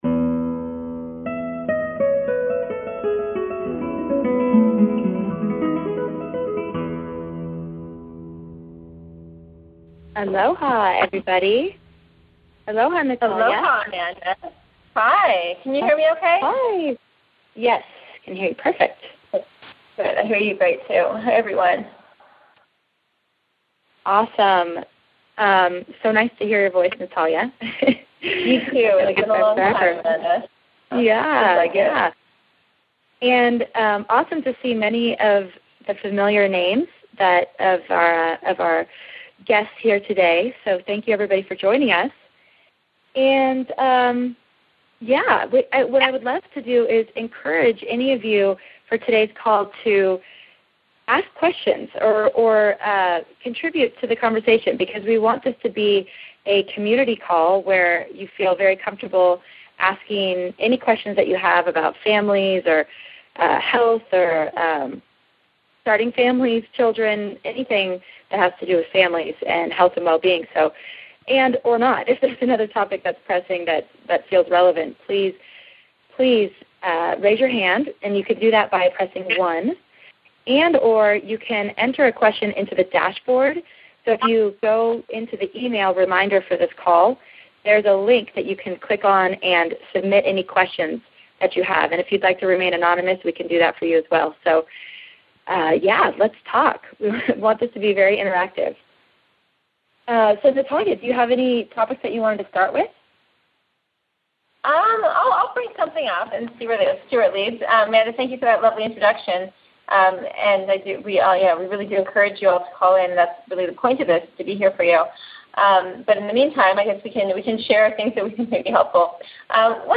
Live Q&A-January 14th, 2014